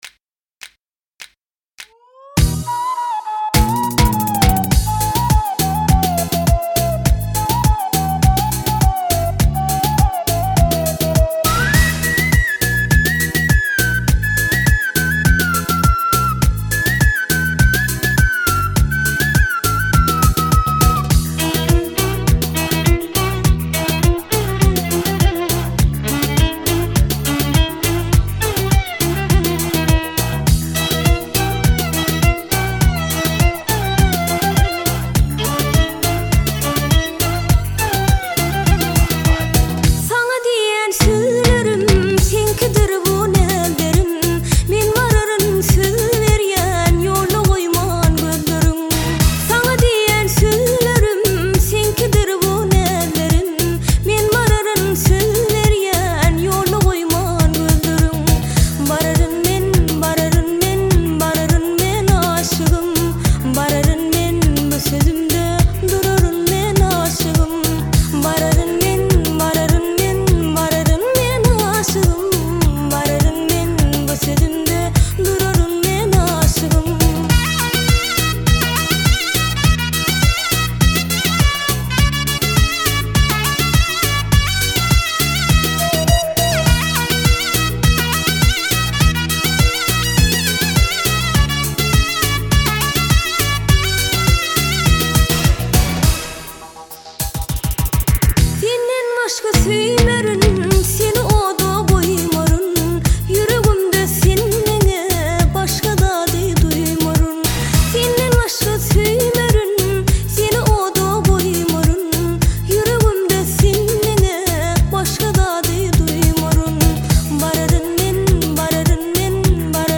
آهنگ زیبای ترکمنی بارارم من (خواهم رفت)